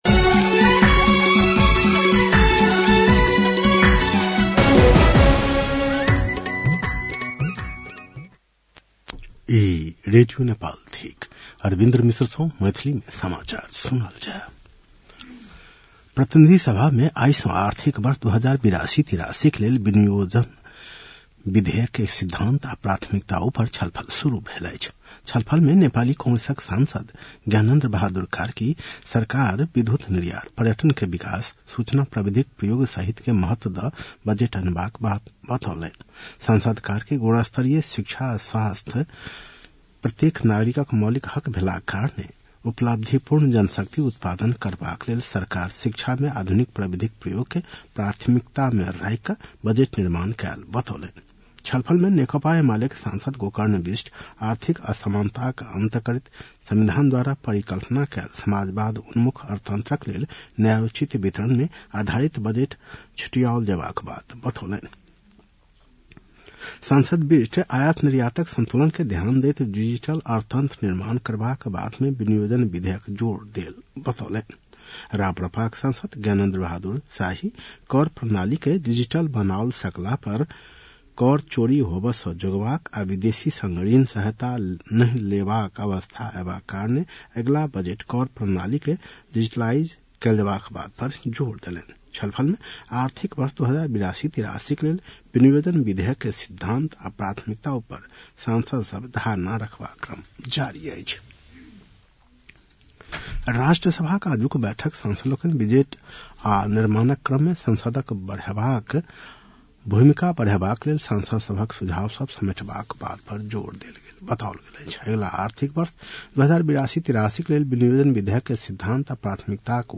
मैथिली भाषामा समाचार : ३० वैशाख , २०८२